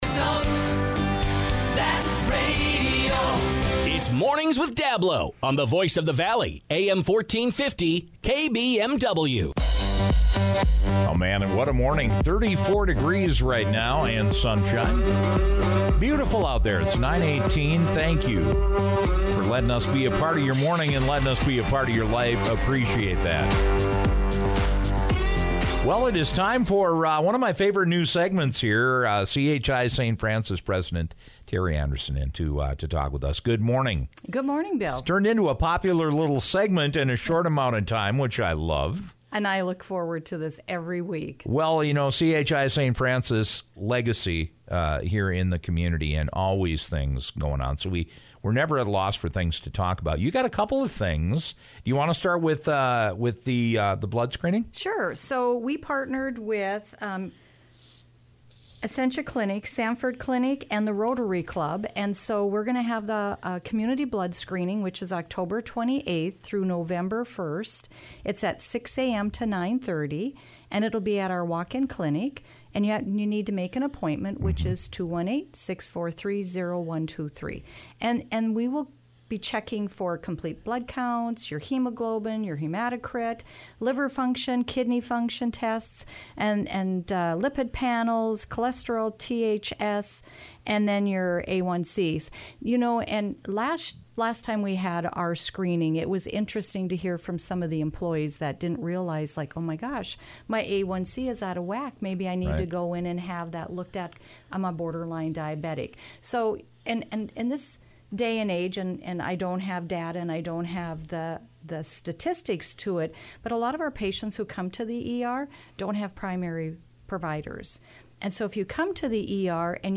Today we talked about Rotary Blood Screening Week next week and got the definitions of “swing beds.” Listen to our conversation below.